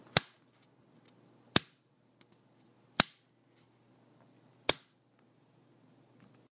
斯特罗系统的开关
描述：打开和关闭我的立体声超低音扬声器开关。
Tag: 开关 音响 压缩机 按钮